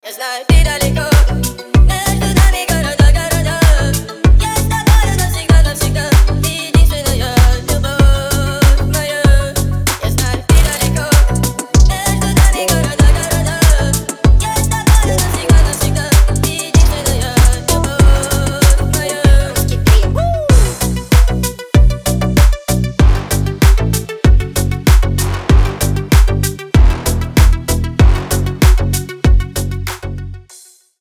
Ремикс
ритмичные